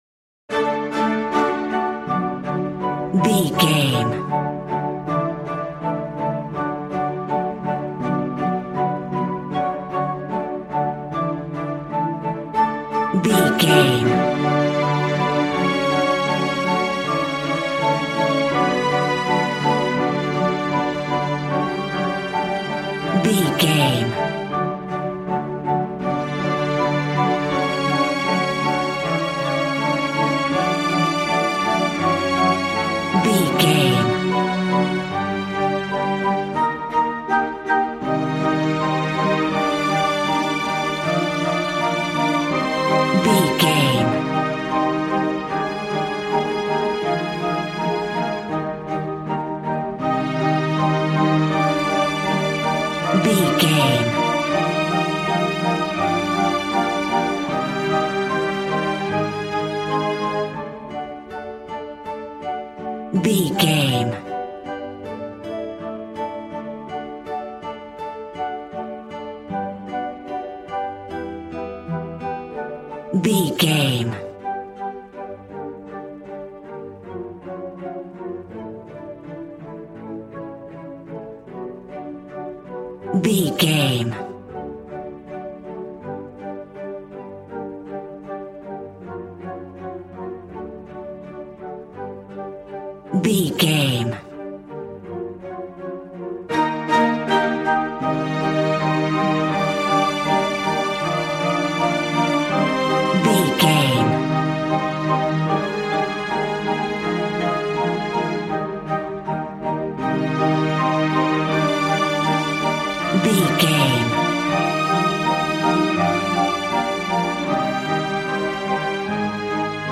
Regal and romantic, a classy piece of classical music.
Aeolian/Minor
regal
strings
violin